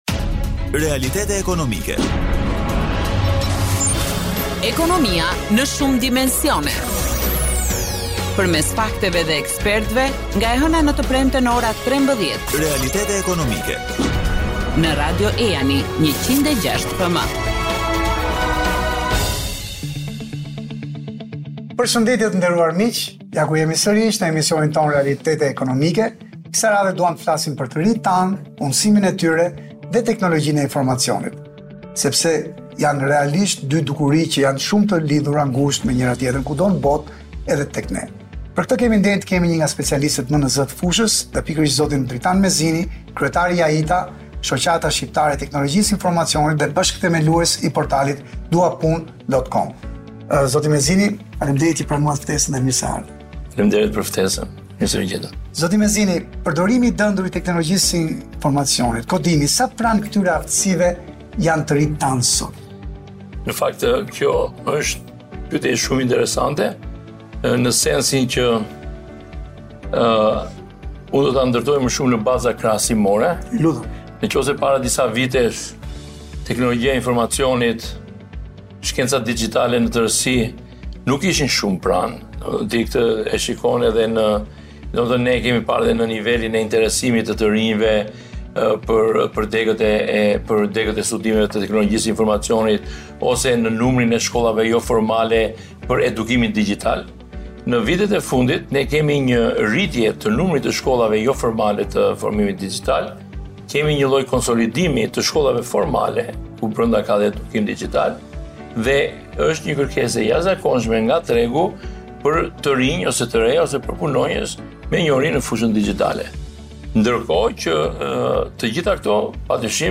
në intervistë për Grupin Mediatik të Kinës